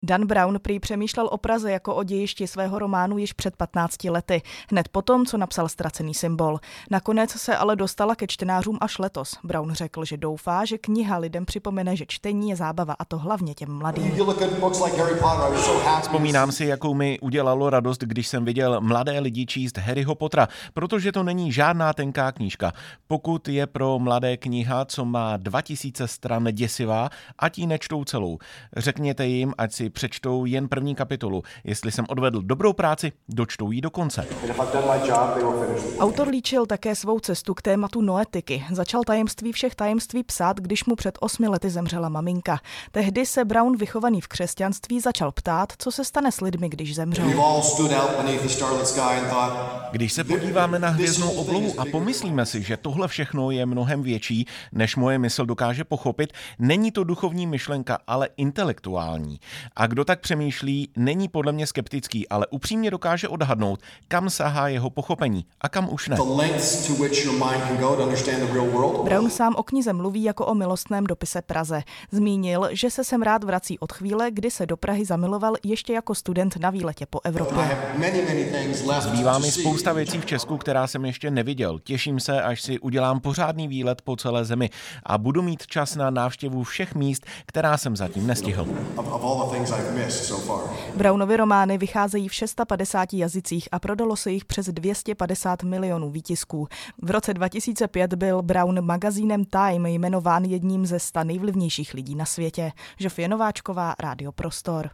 Reportáž ze setkání se spisovatelem Danem Brownem